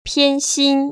[piānxīn] 피앤신  ▶